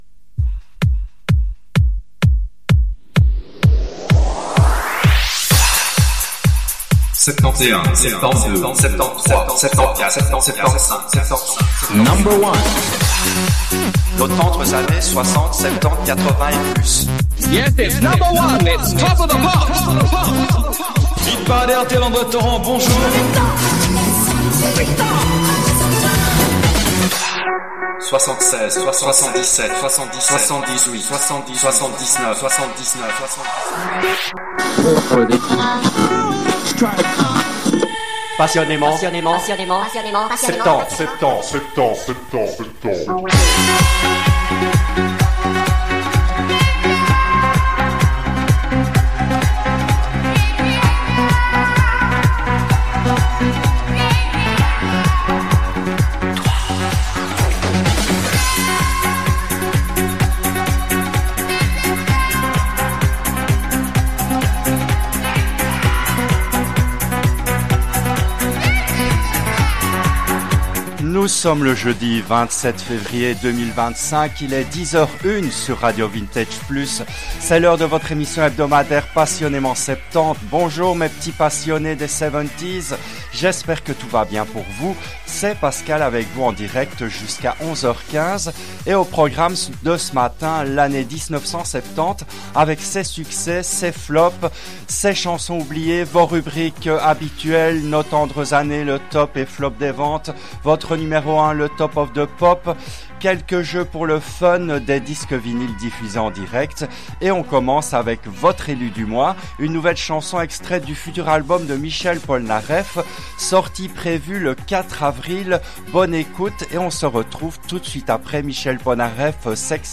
L’émission a été diffusée en direct le jeudi 27 février 2025 à 10h depuis les studios belges de RADIO RV+.